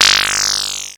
ihob/Assets/Extensions/RetroGamesSoundFX/Shoot/Shoot12.wav at master
Shoot12.wav